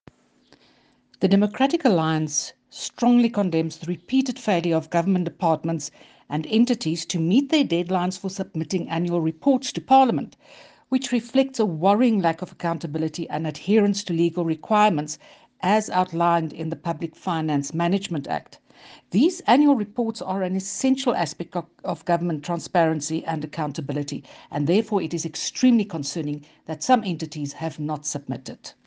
Afrikaans soundbites by Dr Annelie Lotriet MP